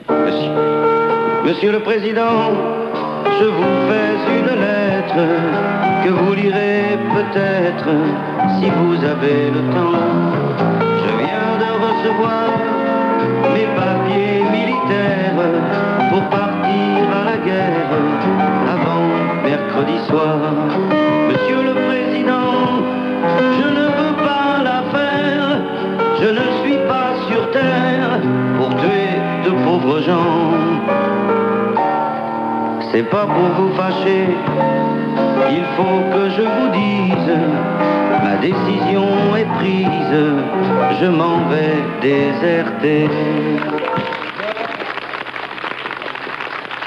Chantera, chantera pas (séquences RTL 1983-1985)